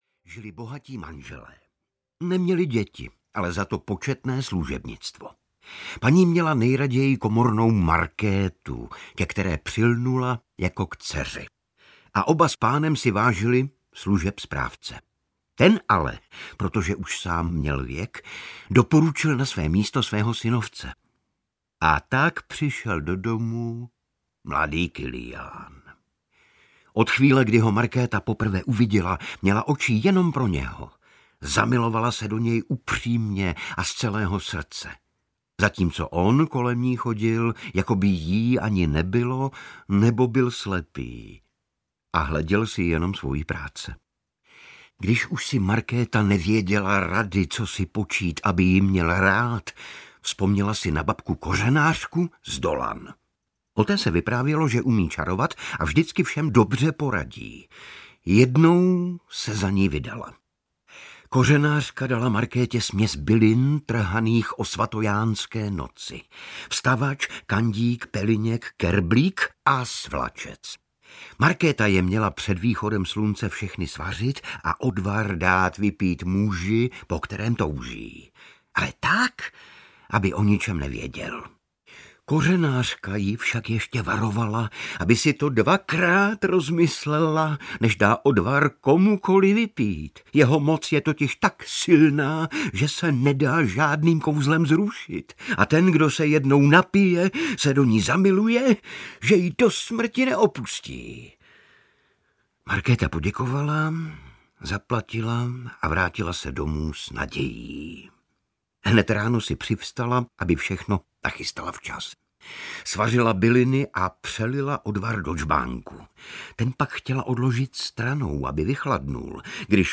Ukázka z knihy
• InterpretIgor Bareš